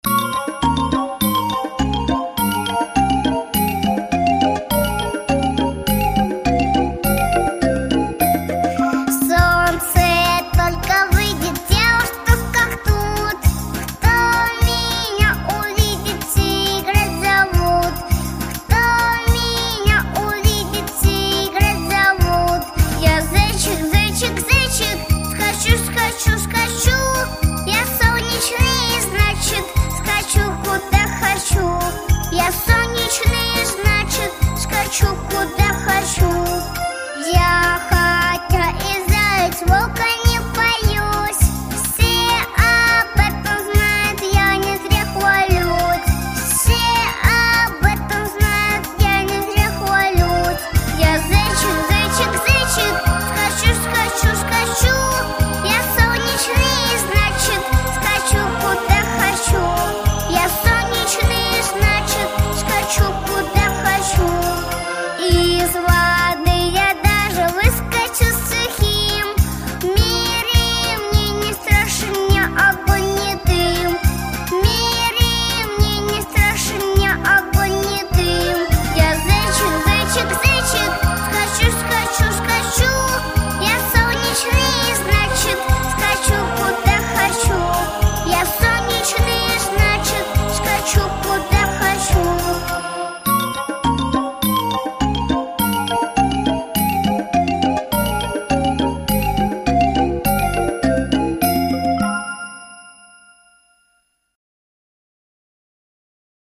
песня про лето.